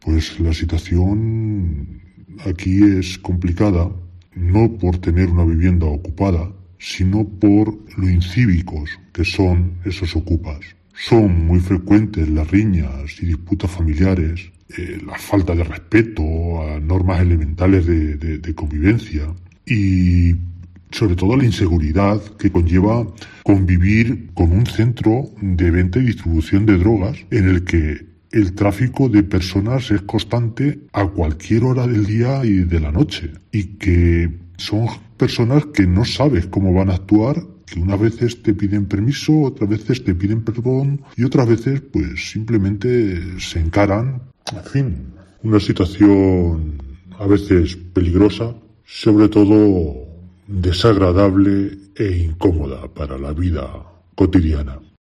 Vecino de una vivienda okupada y punto de venta de droga en Ciudad Real